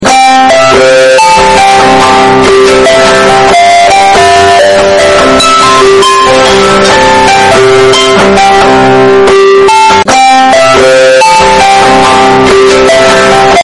Meme Patrick Spongebob Sound Effect, sound effects free download